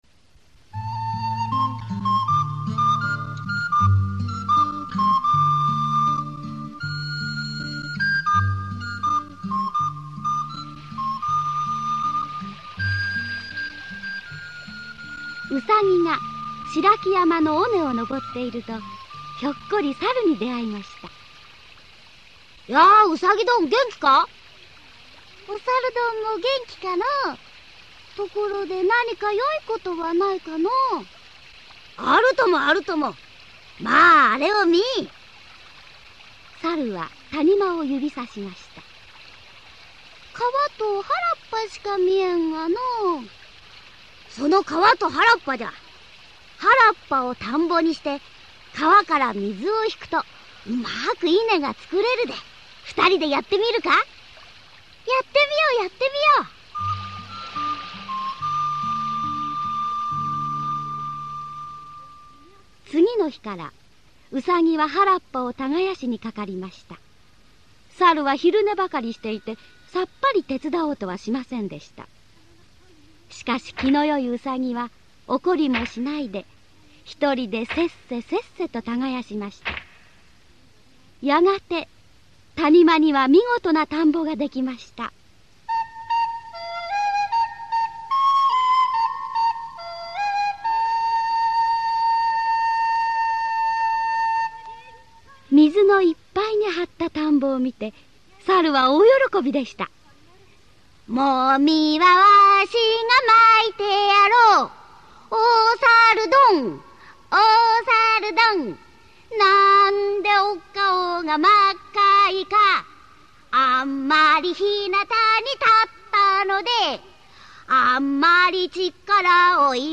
[オーディオブック] うそこきざる